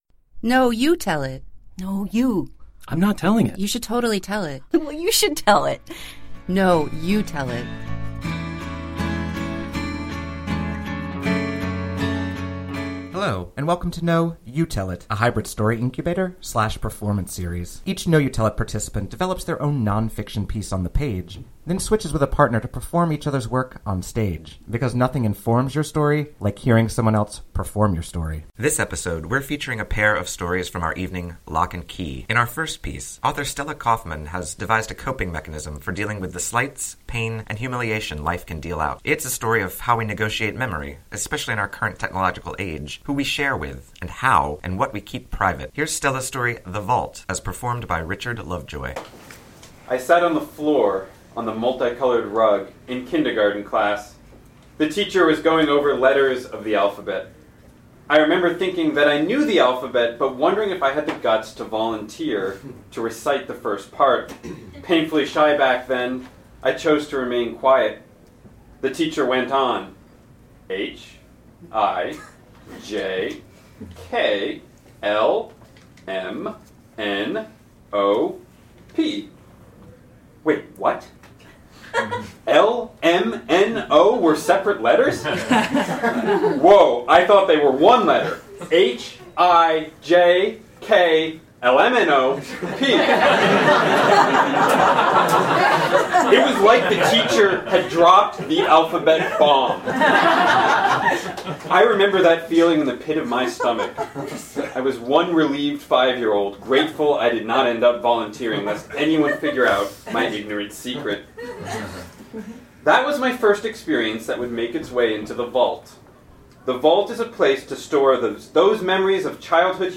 Switched-Up Storytelling Series and Podcast
These stories were performed live on February 17th, 2014 at Jimmy’s No. 43.